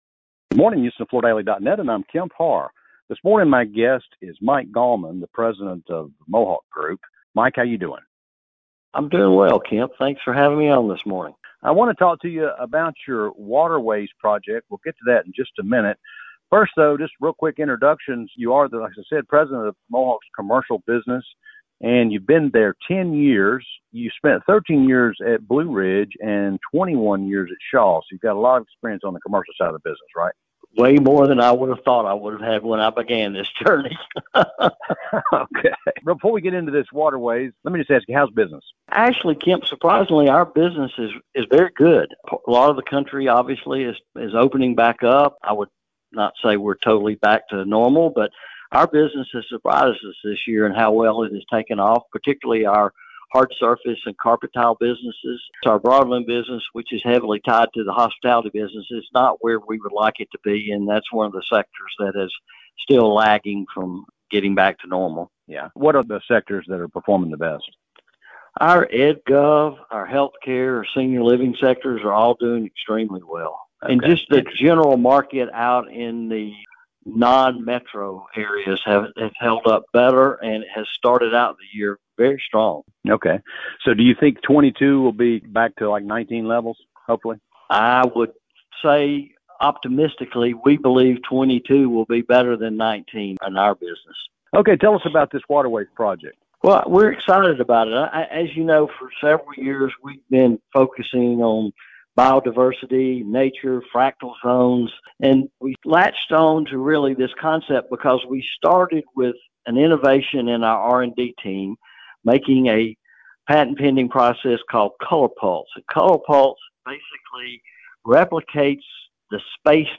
Listen to the interview for more details of this commercially focused exhibit.